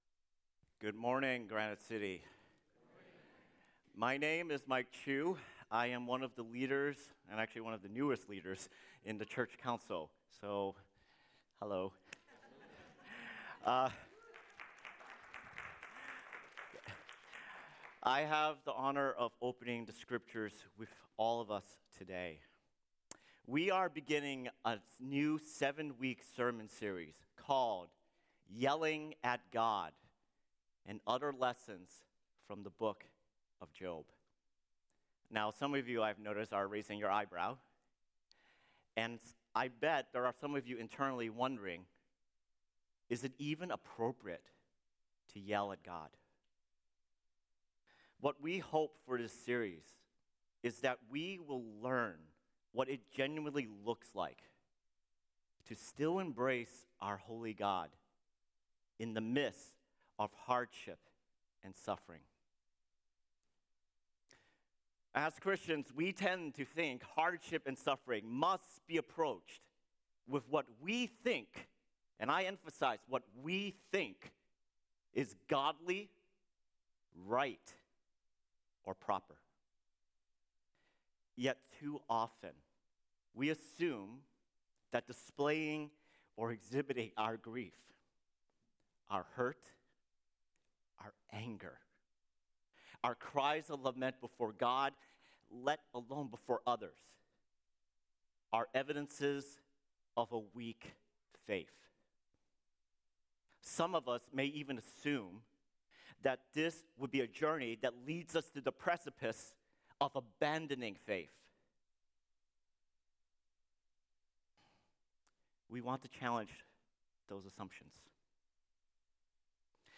Paradise disrupted | Yelling at God Sermon Series | Granite City Church